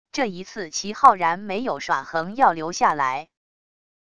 这一次齐浩然没有耍横要流下来wav音频生成系统WAV Audio Player